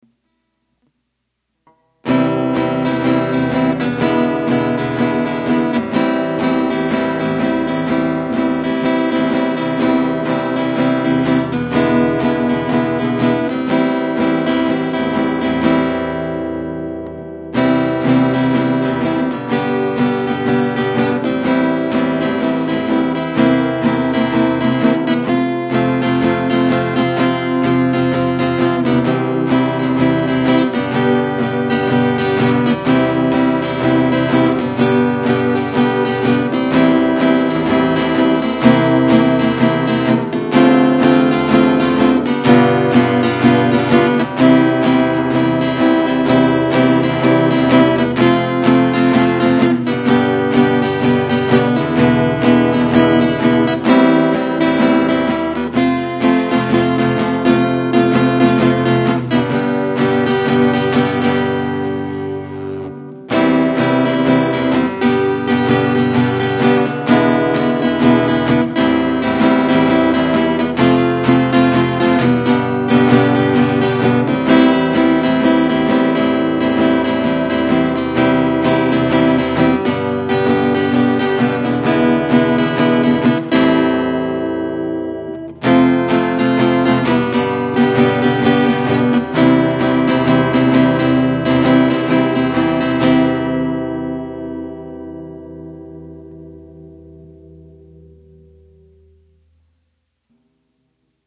昨日書いたやつをギターで弾いてみた。……
上のが三拍子、下のが四拍子。個人的には三拍子のほうがのんびりしてて好き。
こんな感じでコード進行だけ弾いた音源をこれから作っていこうかなと思う。